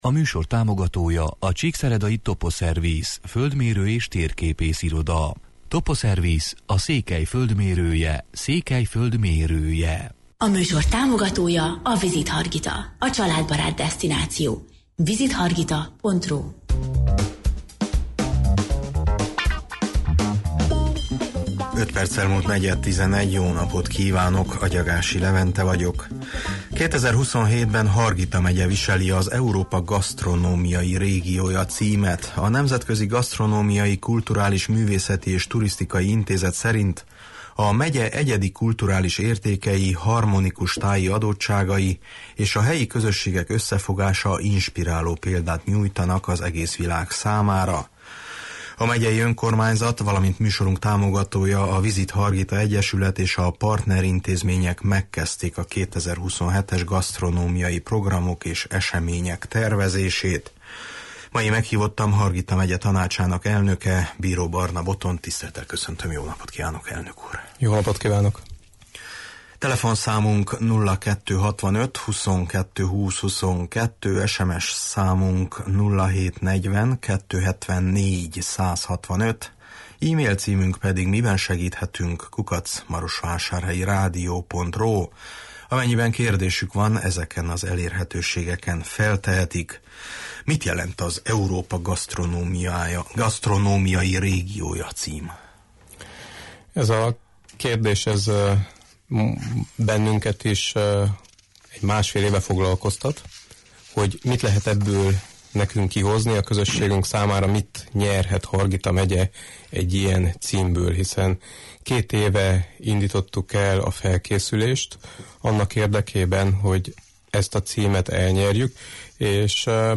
Mai meghívottam Hargita Megye Tanácsának elnöke, Bíró Barna Botond, akivel a rangos címről és az ezzel járó kihívásokról, valamint a megyei önkormányzat tevékenységéről beszélgetünk: